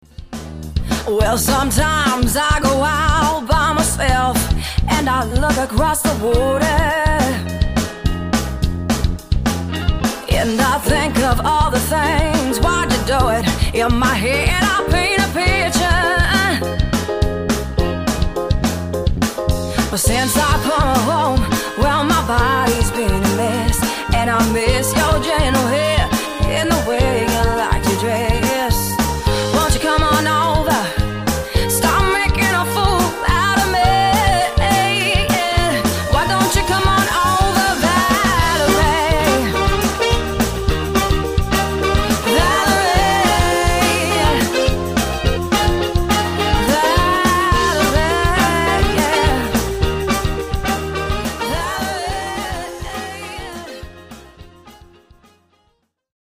Corporate and Wedding Cover Band Hire Melbourne